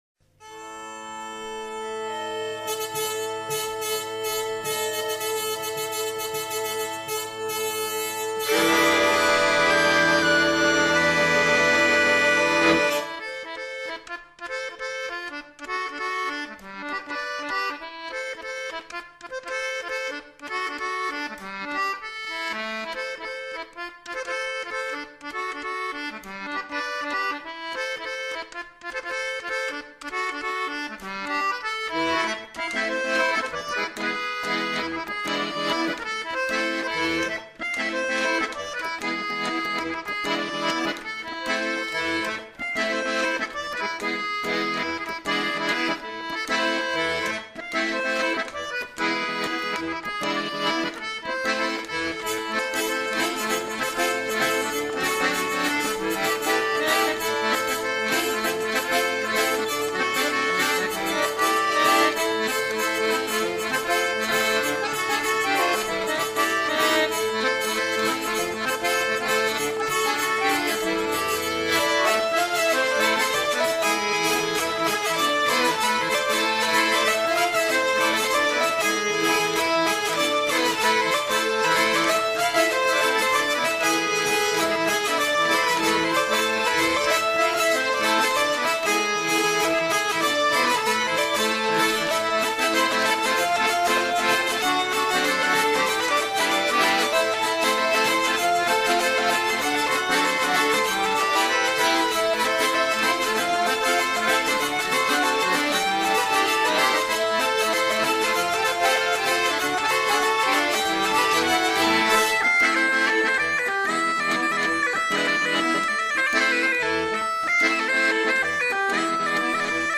Répétition du 03/06/2011 - Module 3 - Bretagne/Dauphiné/Auvergne
scottish folkloriade.mp3